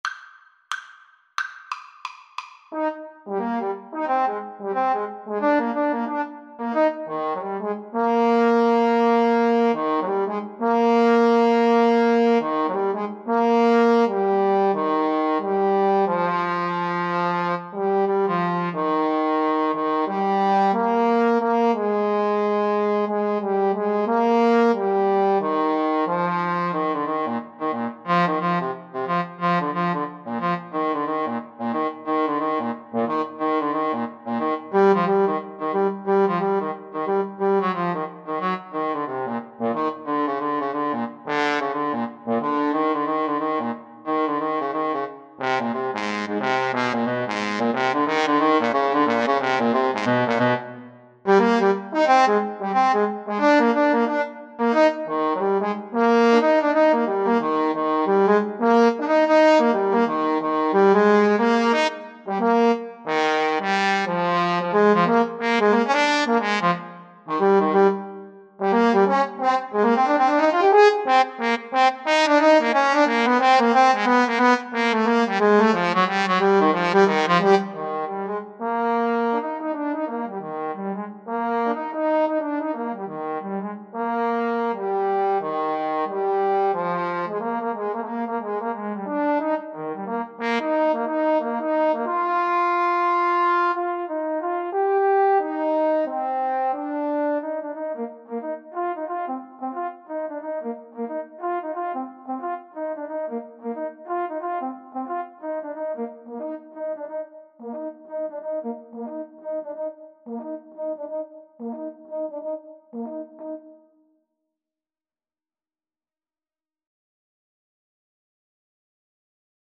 Trombone 1Trombone 2
2/2 (View more 2/2 Music)
Fast and with a swing =c.90